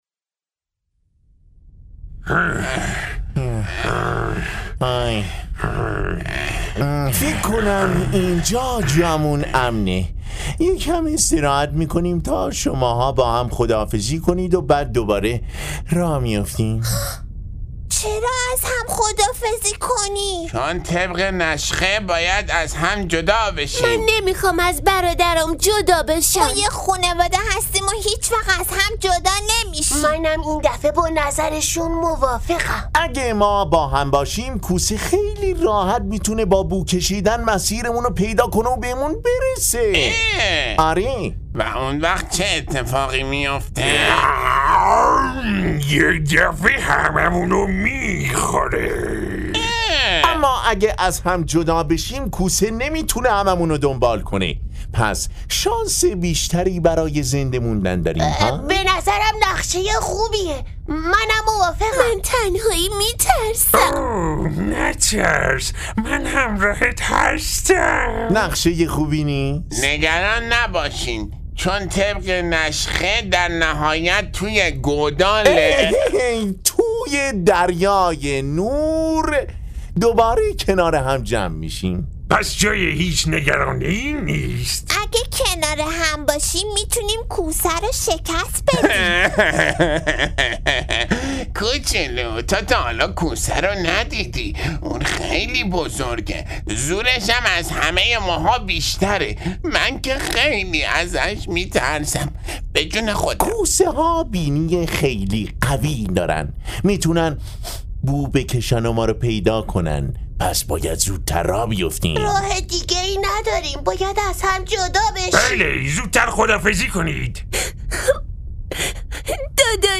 نمایشنامه صوتی در جستجوی نور - قسمت سوم - متفرقه با ترافیک رایگان